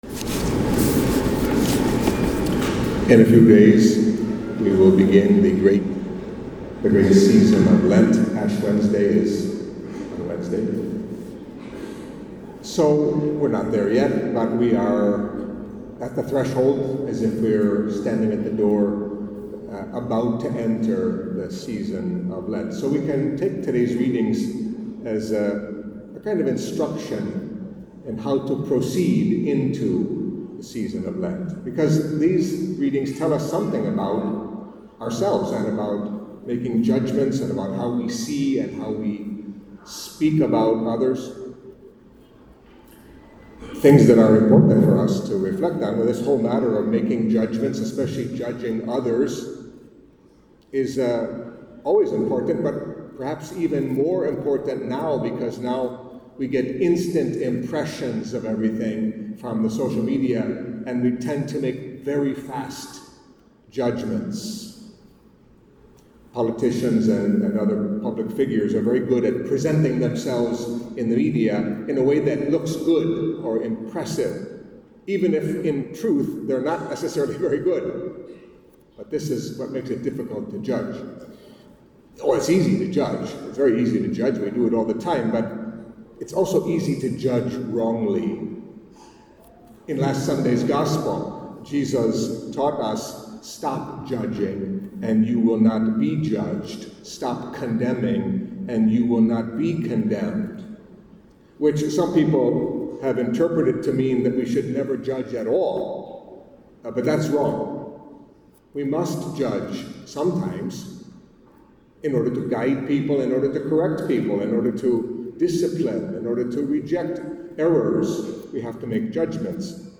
Catholic Mass homily for Eighth Sunday in Ordinary Time